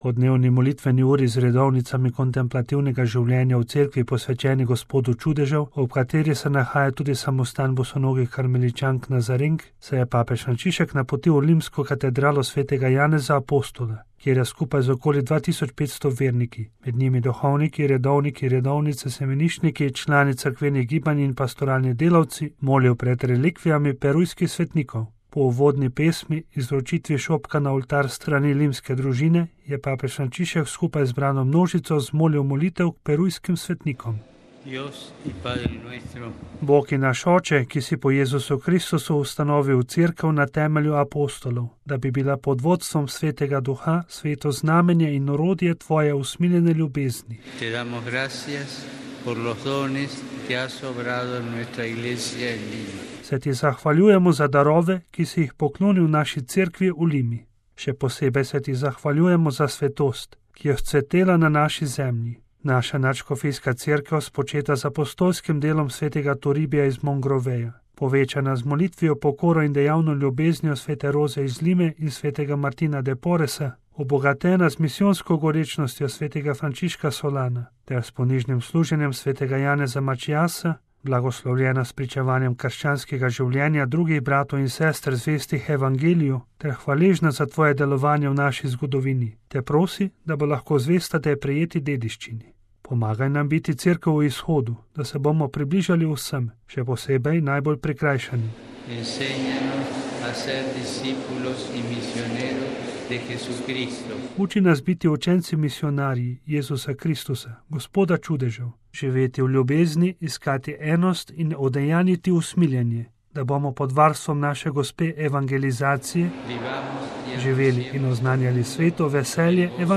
LIMA (nedelja, 21. januar 2018, RV) – Po dnevni molitveni uri z redovnicami kontemplativnega življenja v cerkvi, posvečeni Gospodu čudežev, ob kateri se nahaja tudi samostan bosonogih karmeličank nazarenk, se je papež Frančišek napotil v limsko katedralo sv. Janeza, apostola, kjer je skupaj z okoli 2500 verniki, med njimi duhovniki, redovniki, redovnice, semeniščniki, člani cerkvenih gibanj in pastoralni delavci molil pred relikvijami perujskih svetnikov.